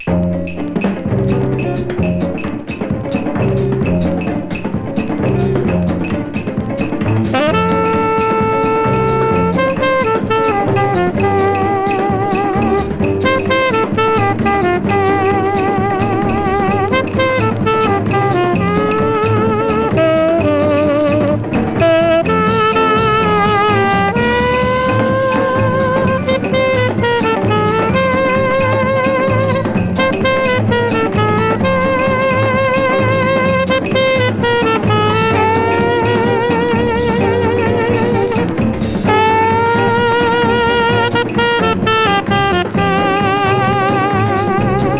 Jazz, Rhythm & Blues　USA　12inchレコード　33rpm　Mono